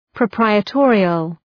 {prə’praıə,tɔ:rıəl}